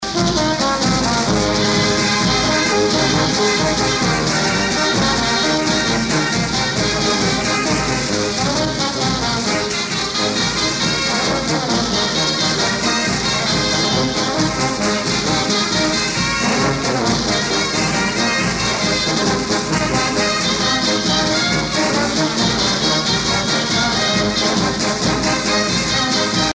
danse : Marinera (Pérou)